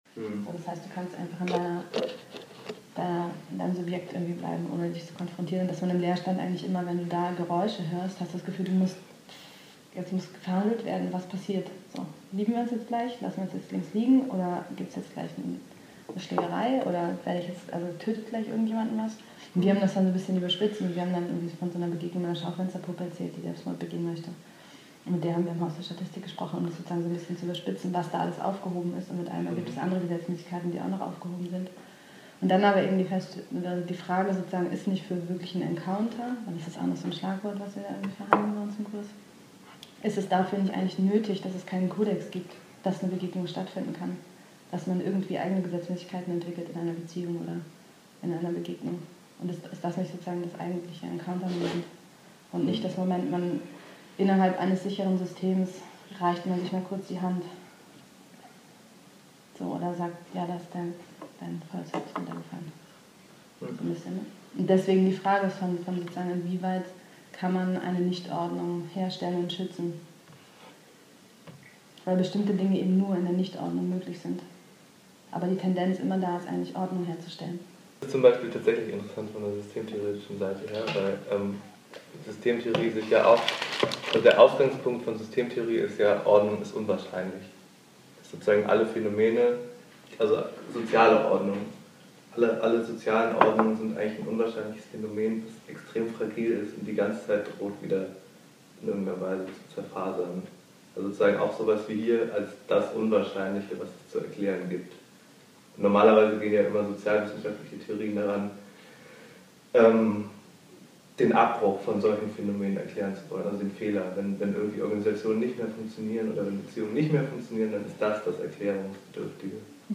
auszüge aus einem küchentalk am 20.jan zu leerstand / ordnung / unordnung / encounter.